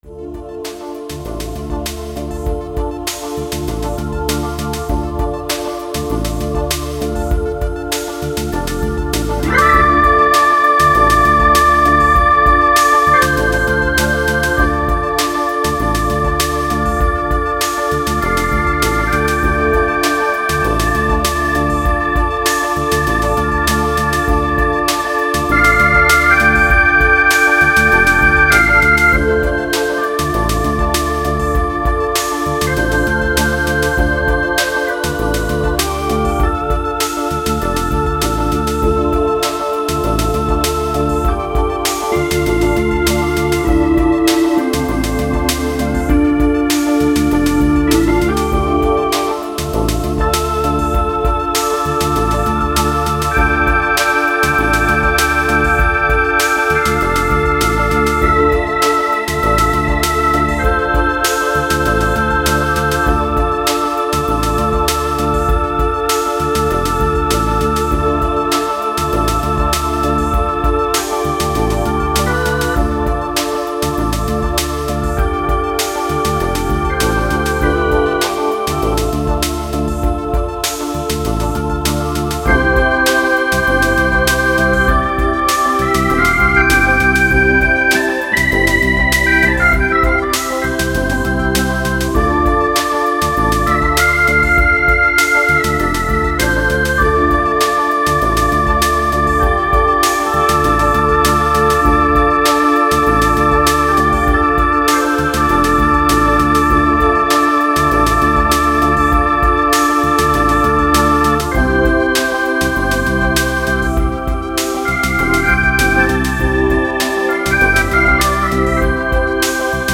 Verträumte Welt.
Tempo:95 bpm / Datum: 17.01.2017